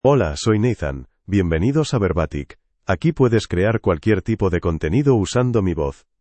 MaleSpanish (Spain)
Voice sample
Male
Nathan delivers clear pronunciation with authentic Spain Spanish intonation, making your content sound professionally produced.